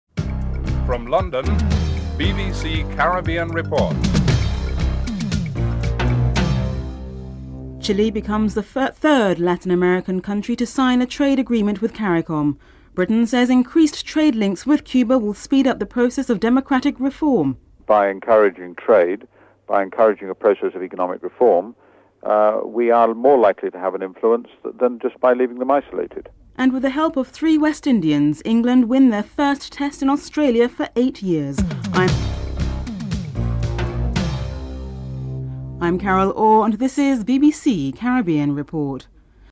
3. Interview with Ralph Maraj on the possibility of such a trade agreement and whether these agreements were really being utilised (00:59-03:32)
4. Interview with Ian Taylor to explain what the investment promotion and protection agreement between Britain and Cuba is designed to achieve (03:33-07:23)